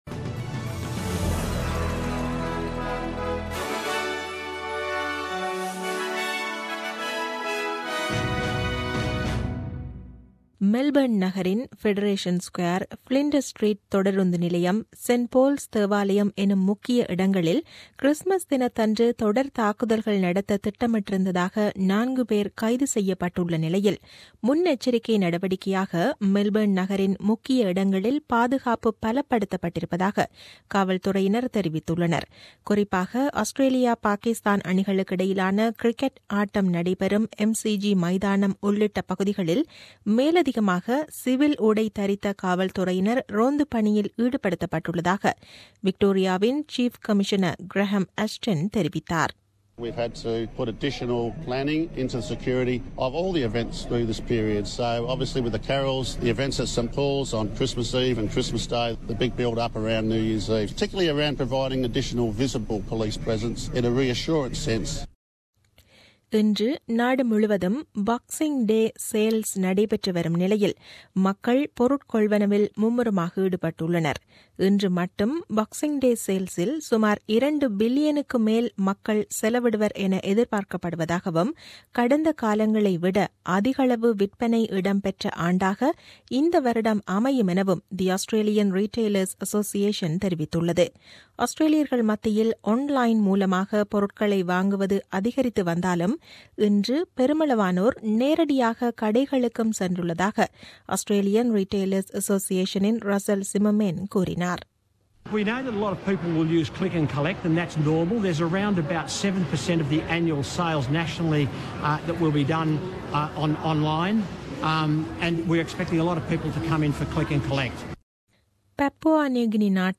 The news bulletin aired on 26 Dec 2016 at 8pm.